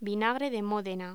Locución: Vinagre de Módena
voz